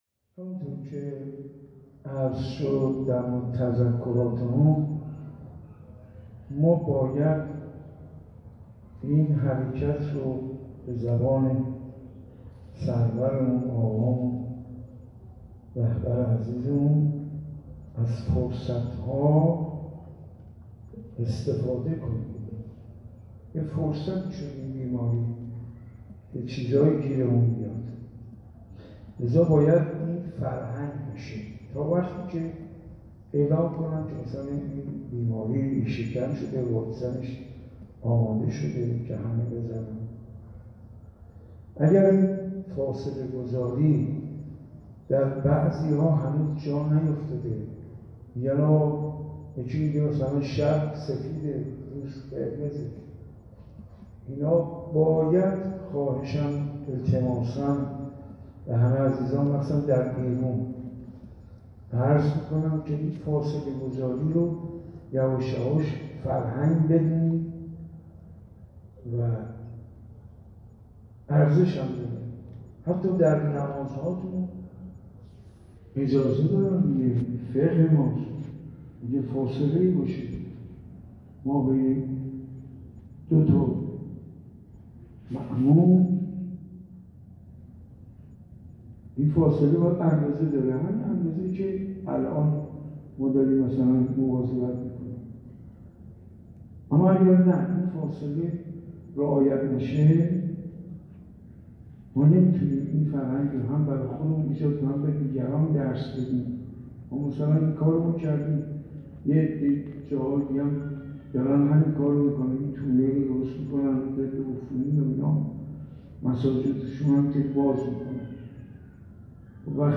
دعای ابوحمزه ثمالی - مسجد ارک تهران